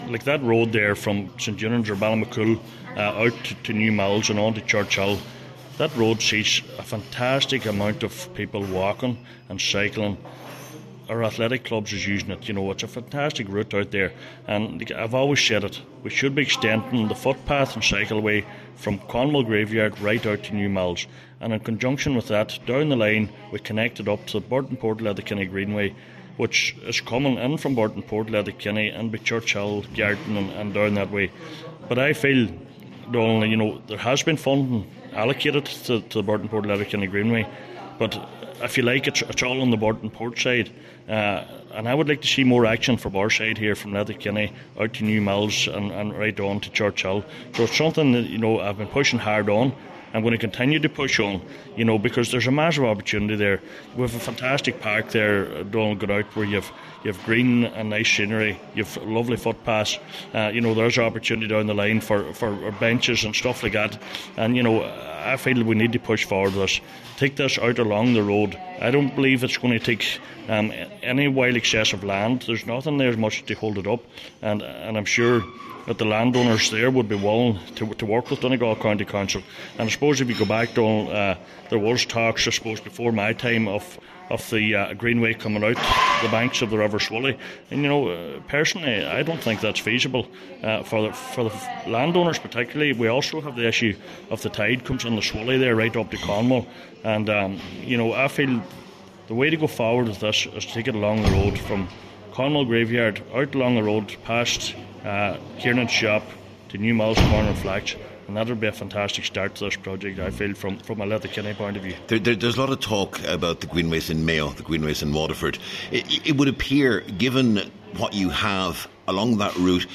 Cllr Donal Mandy Kelly told a meeting of Letterkenny Milford Municipal District that there is huge potential there to develop one of the best greenways in the country.